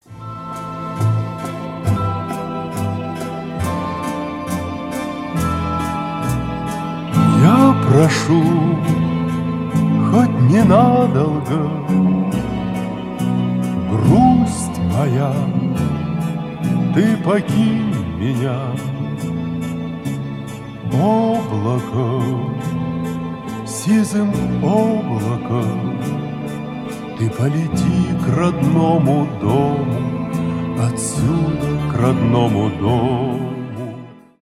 душевные
ретро